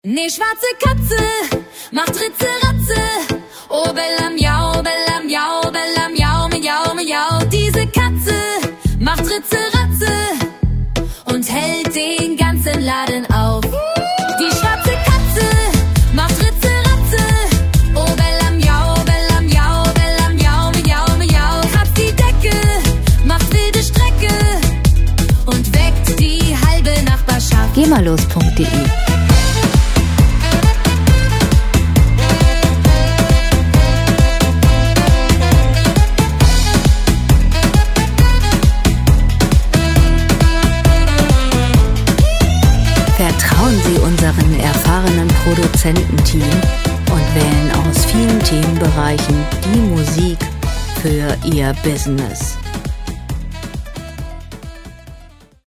Gema-freie Kinderlieder
Musikstil: Reggaeton
Tempo: 135 bpm
Tonart: A-Moll
Charakter: schwungvoll, lebendig